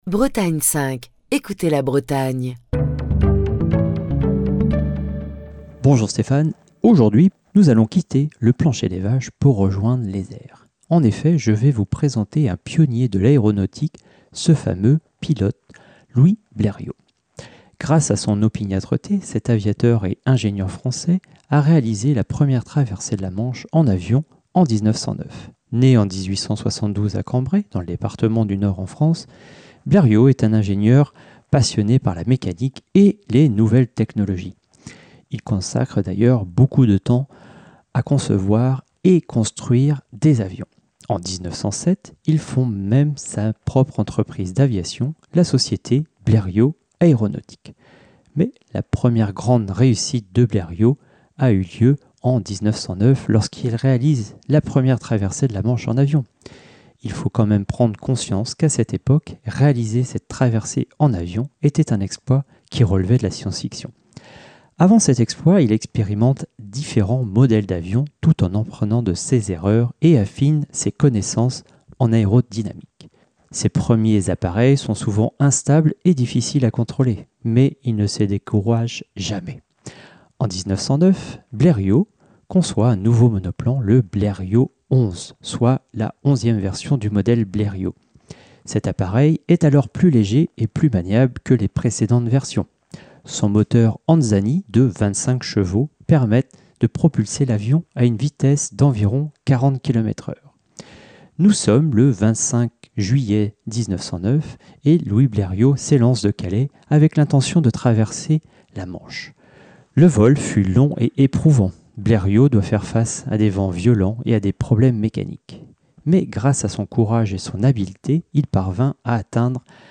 Chronique du 16 octobre 2024.